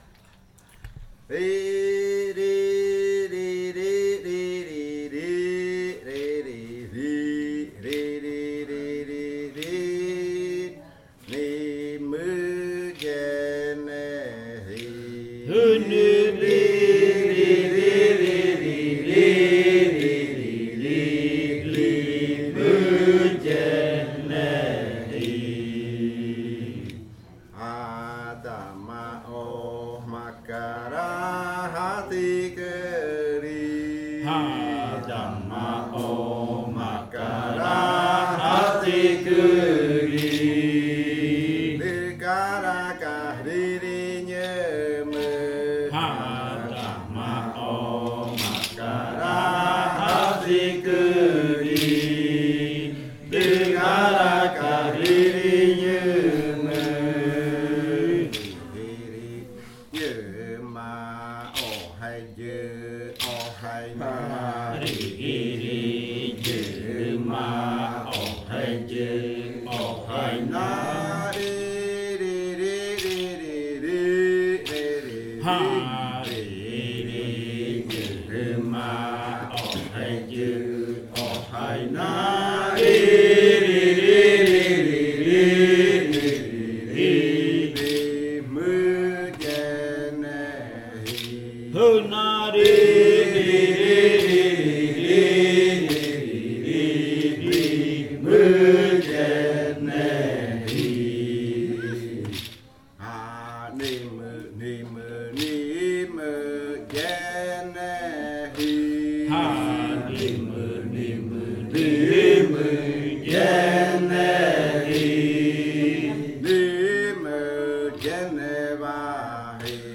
Canto saltar de la variante jimokɨ
Leticia, Amazonas
con el grupo de cantores sentado en Nokaido.
with the group of singers seated in Nokaido.